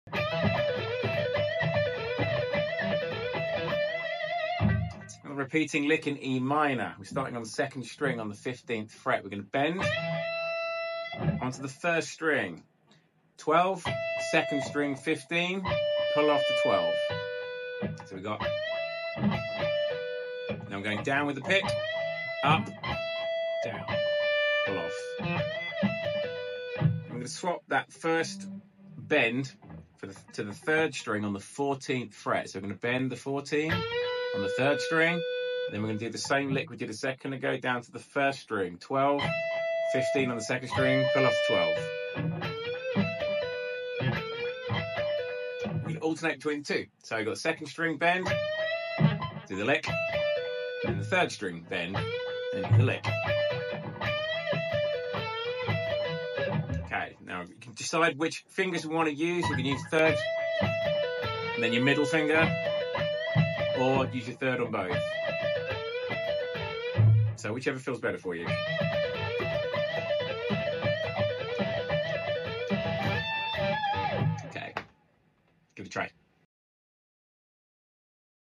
Repeating bendy lick in E sound effects free download
Repeating bendy lick in E minor.